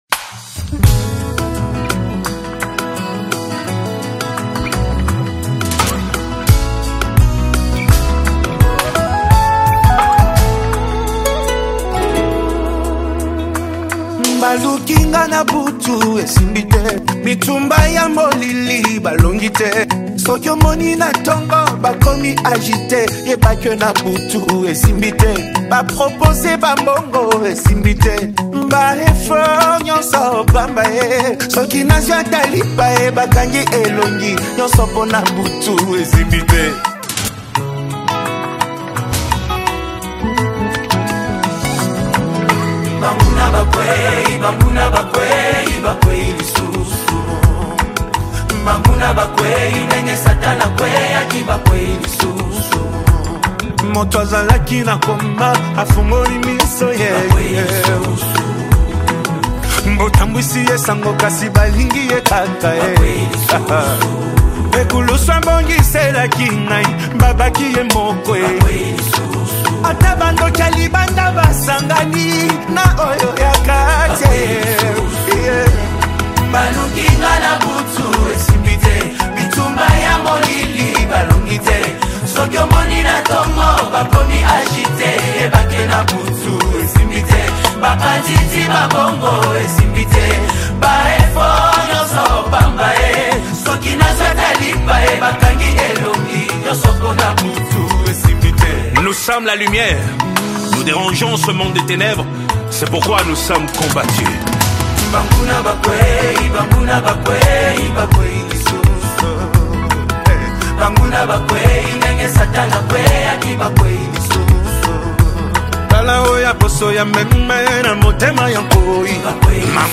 Congo Gospel Music
SOULFUL VOCALS, and DYNAMIC WORSHIP ARRANGEMENT